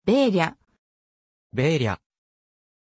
Pronuncia di Bella
pronunciation-bella-ja.mp3